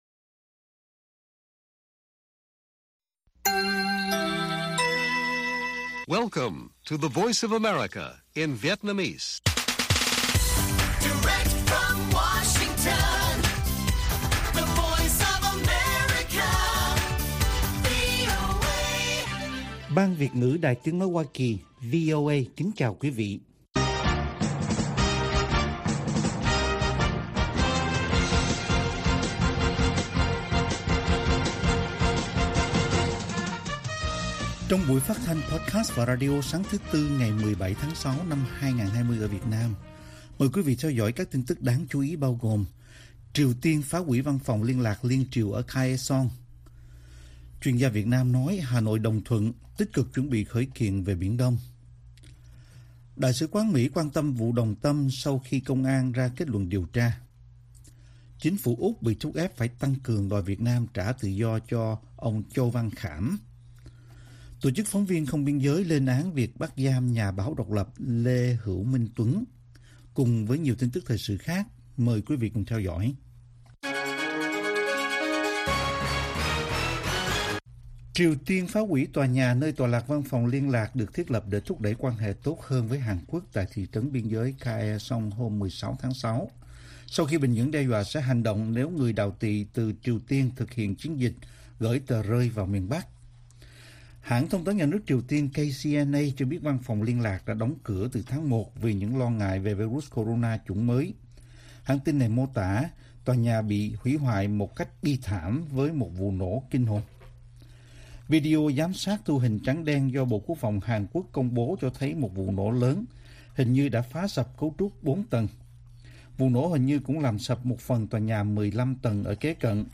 Bản tin VOA ngày 17/6/2020